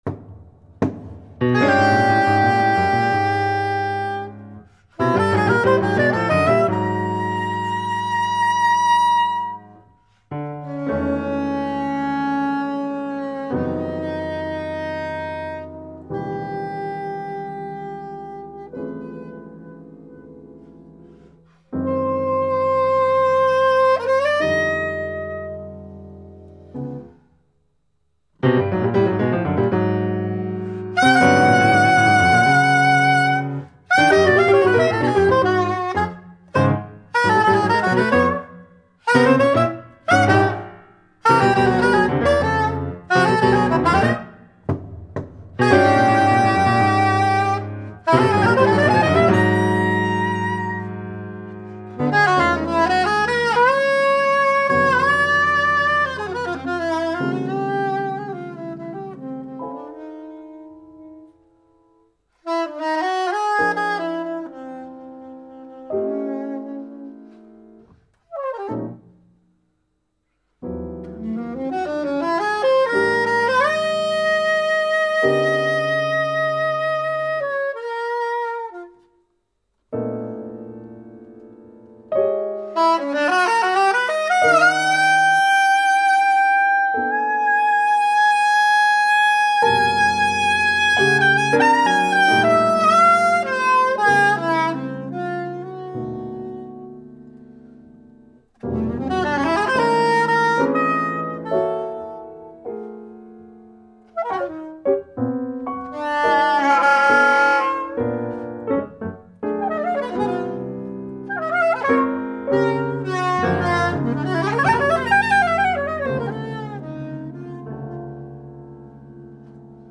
(Soprano sax. Version complète)